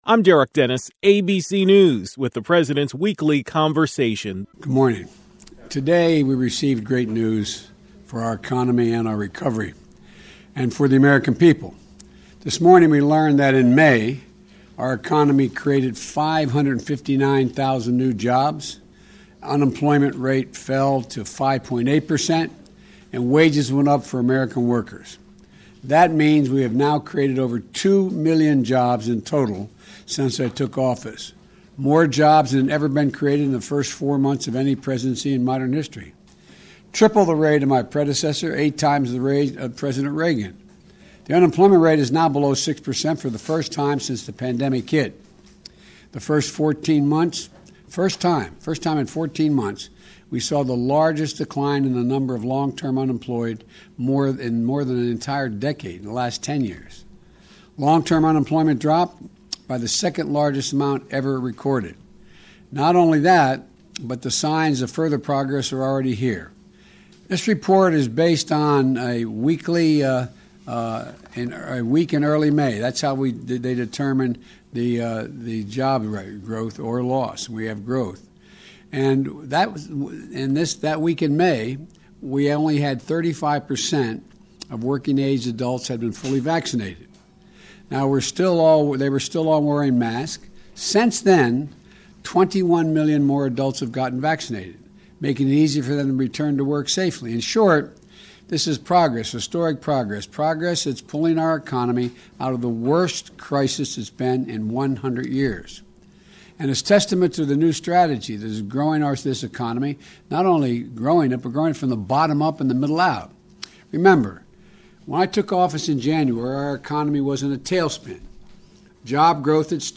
President Joe Biden discussed the most recent Jobs Report.
Here are his words: